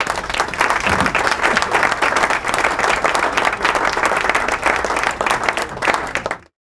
aplauz.wav